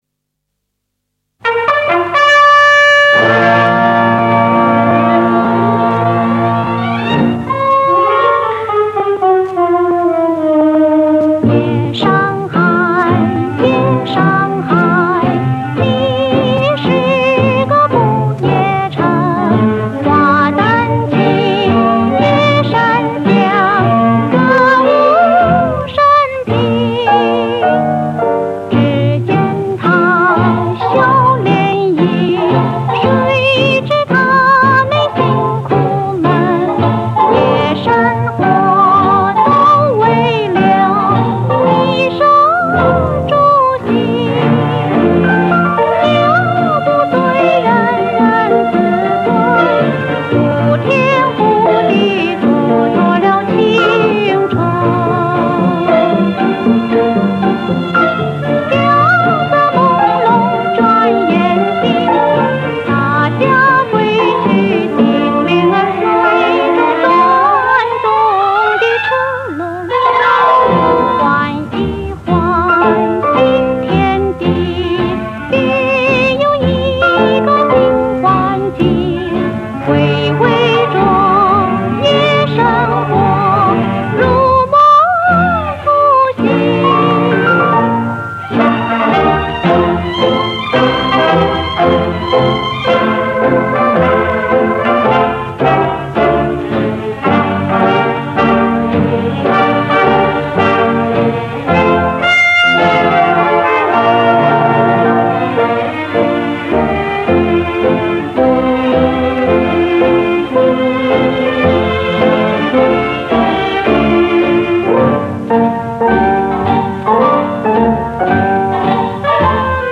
[音乐画廊]记忆中的那个年代(老上海的靡靡之音)